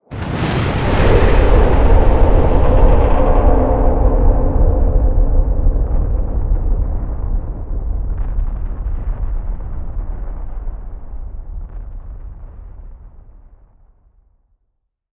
fx_crawlerexplosion_b.wav